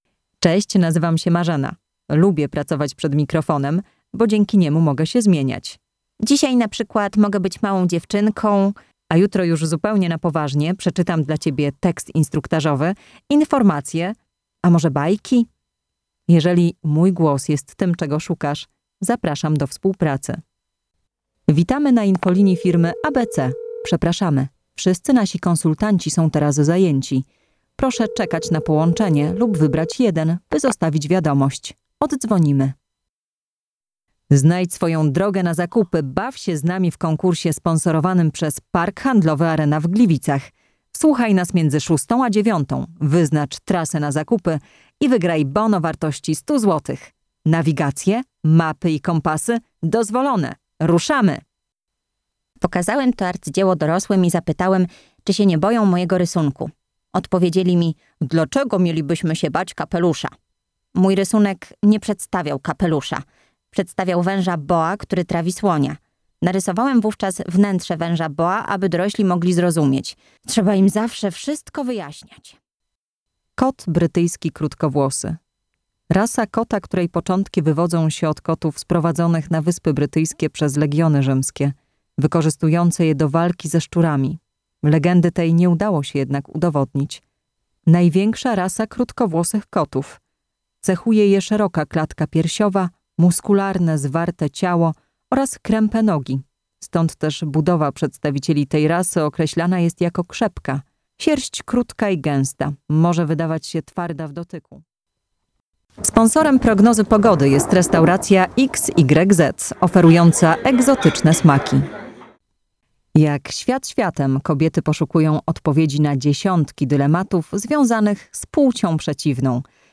Female 20-30 lat
A mature, decisive voice - but when needed, she can become a child.
Portfolio głosowe